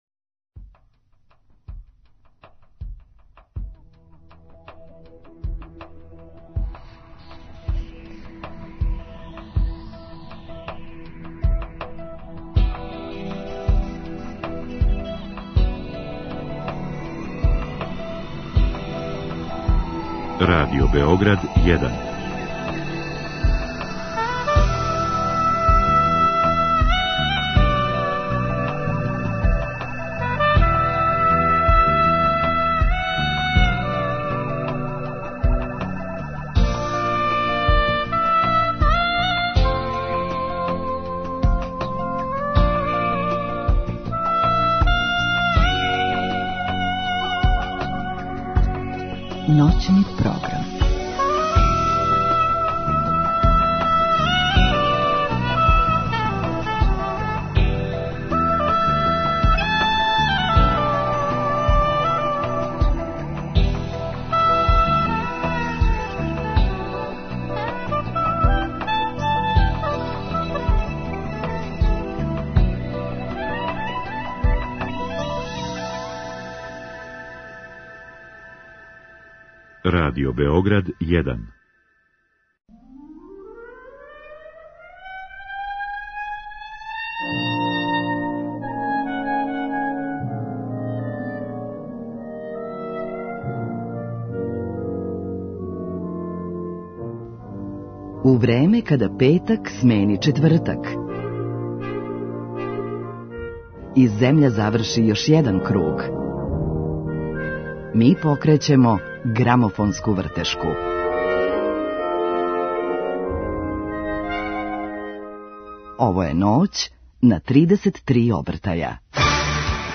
Овонедељно издање 'Ноћи на 33 обртаја' биће музички наставак церемоније отварања Народног музеја у Београду, а слика која је узета као мотив свечаности је 'Девојка у плавом' Ђуре Јакшића. Због тога ћемо и у нашој Ноћи слушати плоче и песме инспирисане плавом бојом.
У другом сату слушамо најпопуларније песме са Билбордове листе, а за крај новитет на винилу.